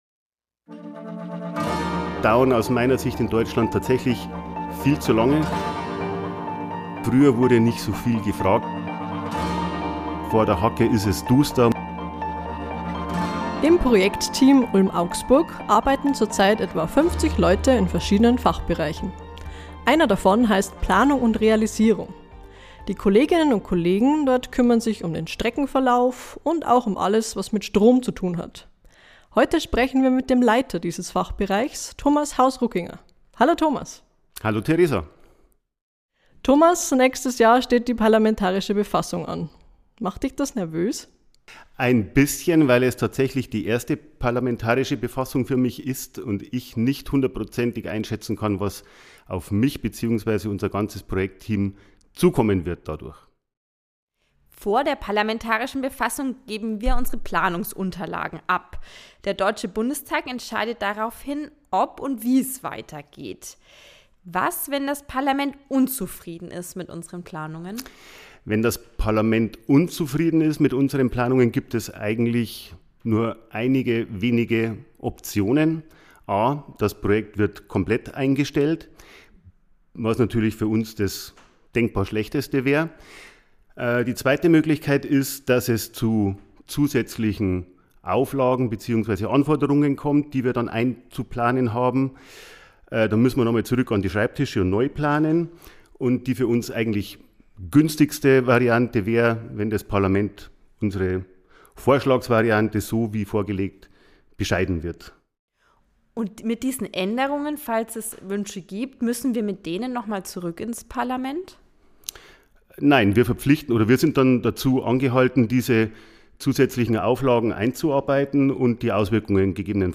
Neun Planungsphasen müssen Infrastrukturprojekte in Deutschland durchlaufen. Bei Ulm–Augsburg ist die zweite, die Vorplanung, demnächst beendet. Ein Gespräch